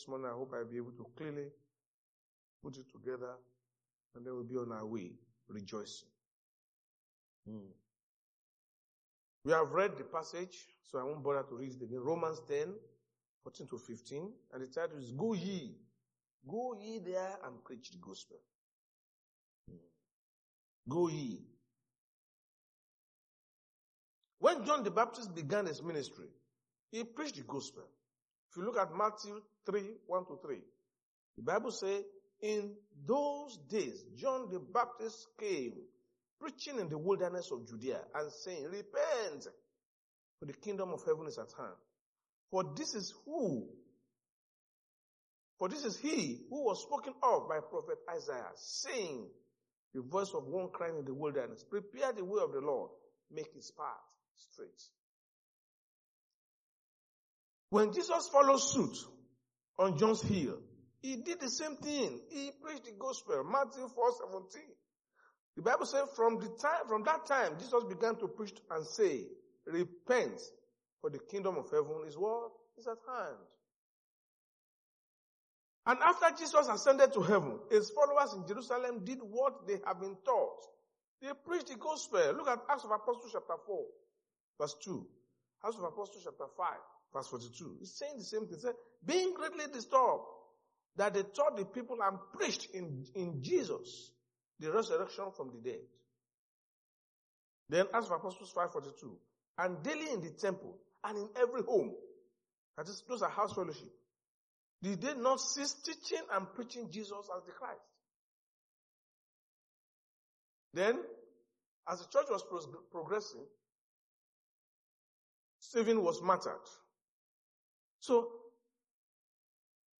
Sunday Sermon: Go Ye And Preach The Word!
Service Type: Sunday Church Service